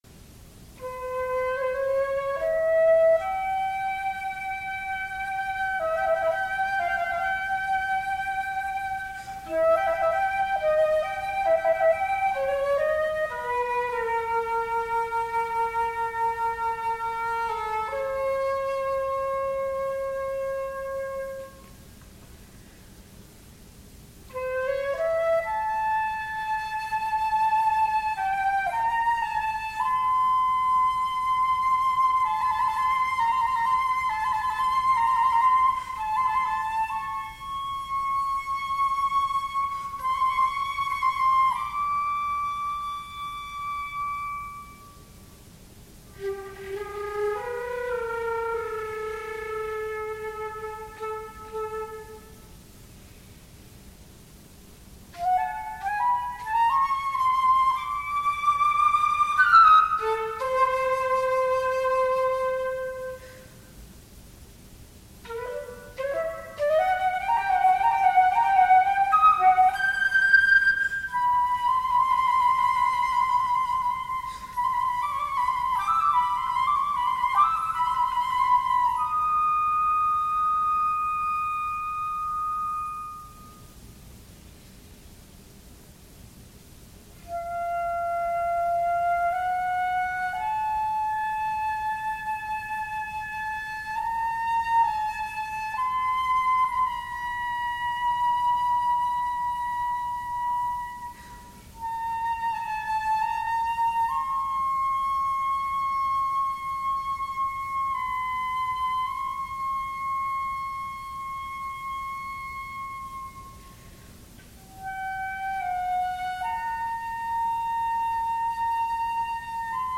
In requesting a piece from me, she asked for something that was slow and lyrical, “like Syrinx”, but also fast to give her a chance to show off.